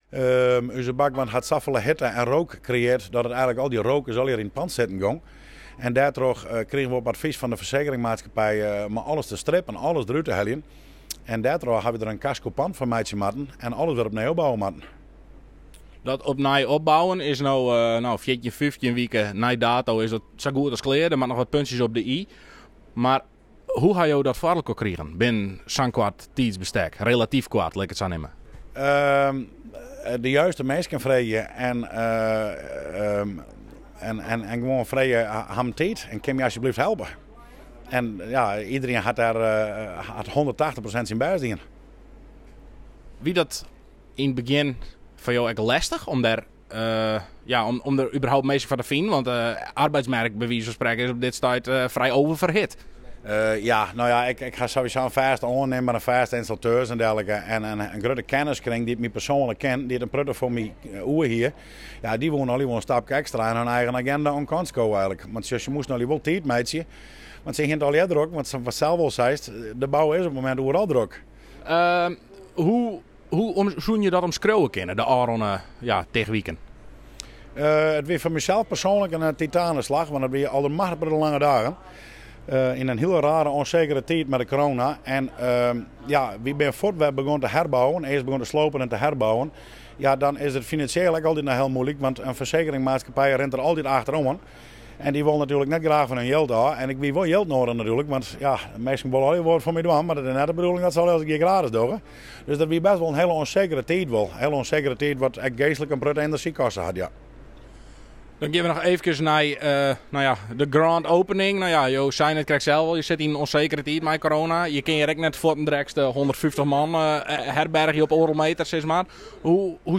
in gesprek met verslaggever